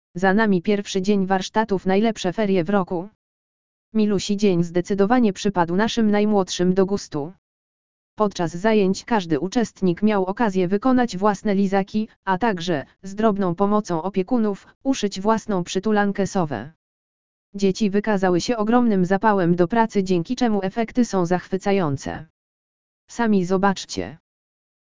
audiolektor_ferie_1.mp3